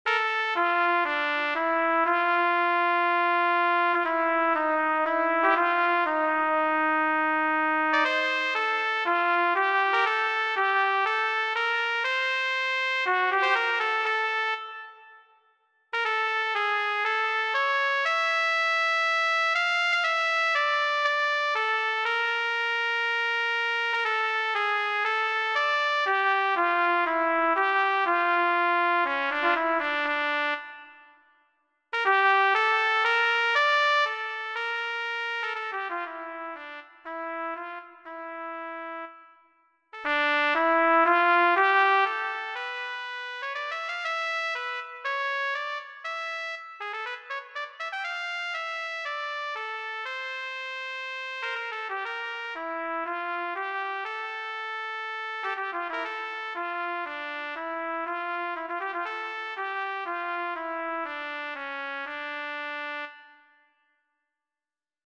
Trumpet Studies & Etudes
Audio with ornaments
Tech16-with-ornaments.mp3